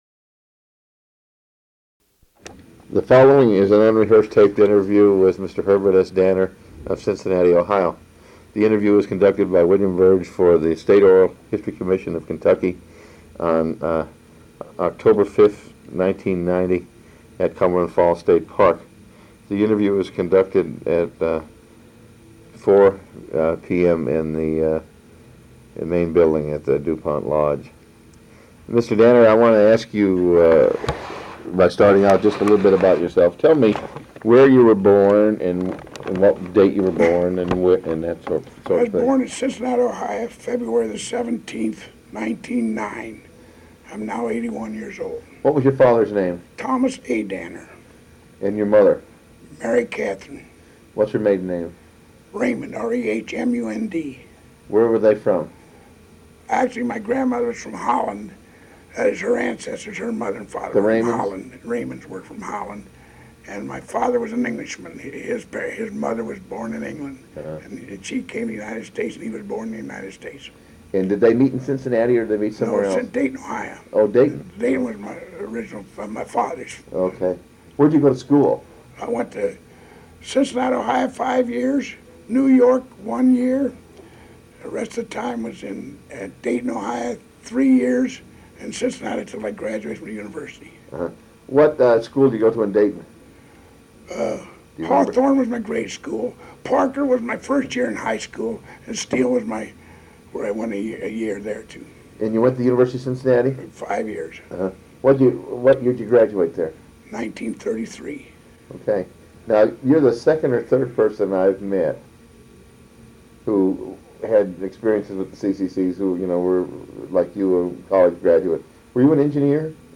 C.C.C. (Civilian Conservation Corps) Oral History Project